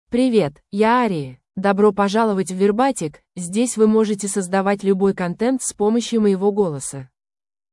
Aria — Female Russian AI voice
Aria is a female AI voice for Russian (Russia).
Voice sample
Listen to Aria's female Russian voice.
Aria delivers clear pronunciation with authentic Russia Russian intonation, making your content sound professionally produced.